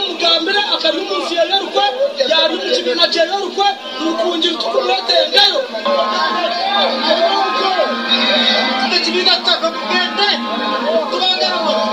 The voters repeatedly shouted him down, demanding that he “go back to Bugisu,” and accusing him of spearheading schemes that allegedly pushed Besigye out of the party he founded.